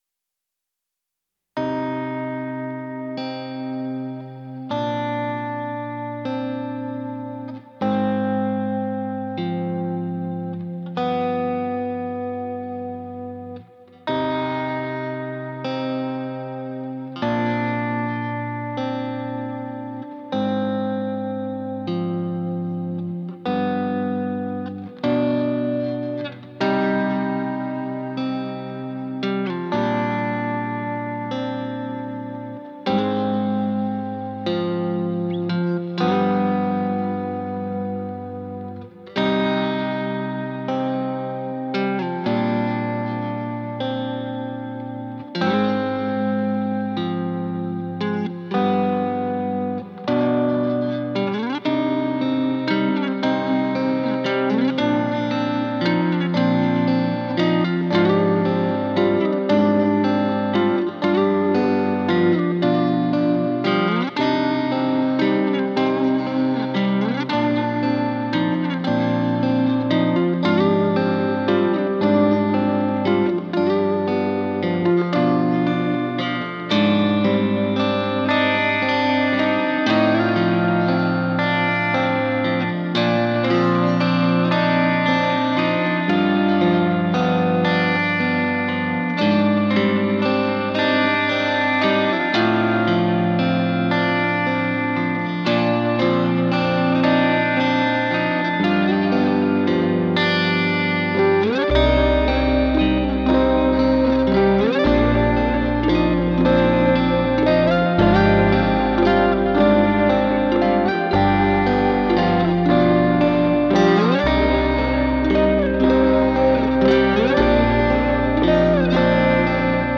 بهترین گروه پست راک تمام دوران ها